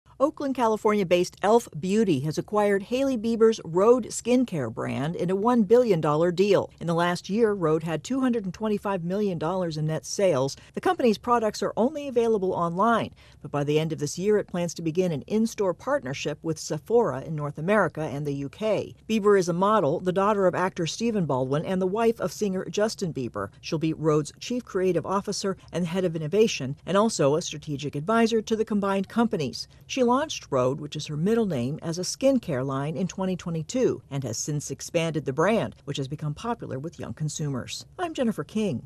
reports on a high profile cosmetics deal.